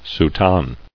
[sou·tane]